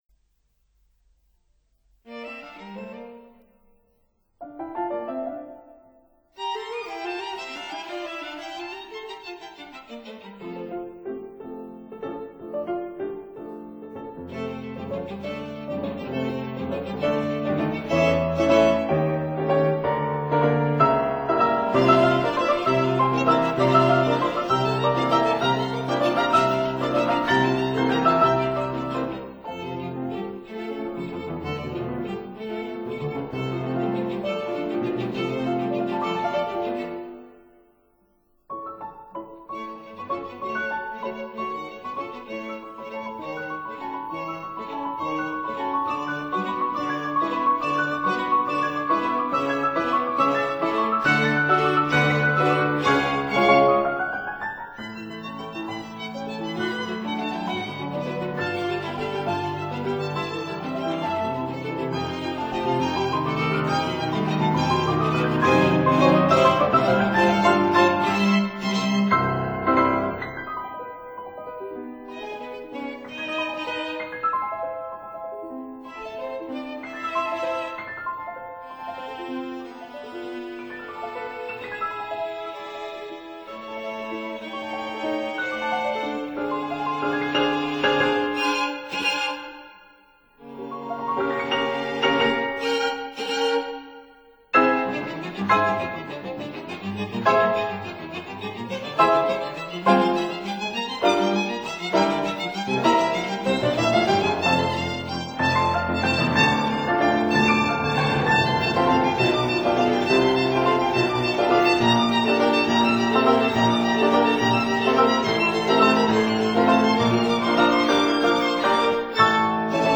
violin
cello
piano